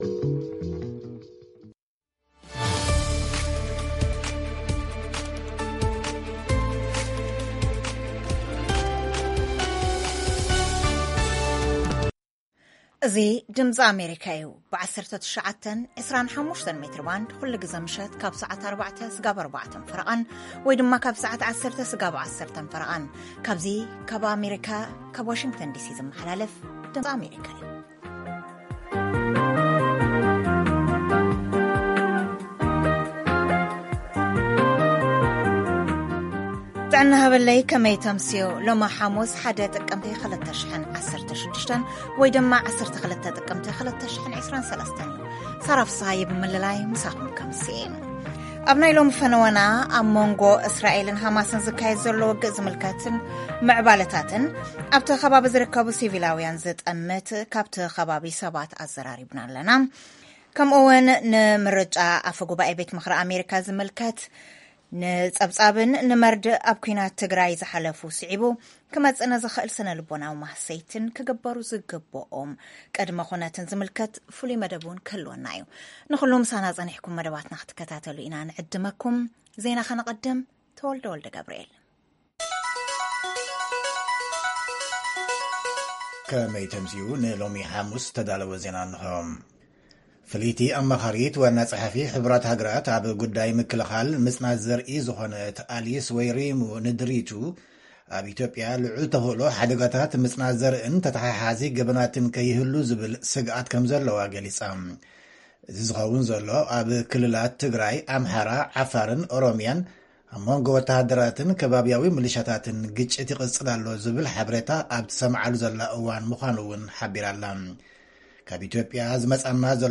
ፈነወ ድምጺ ኣመሪካ ቋንቋ ትግርኛ 12 ጥቅምቲ 2023 ኣህጉራዊን ዓለምለኸ ዜና ዕላል ምስ ጻንሒት ምስ ኣብ ከተማ ኣሽከሎን ዝነብር ኢትዮጵያዊ ኣይሁዳዊ መደብ ጥዕና የጠቓልል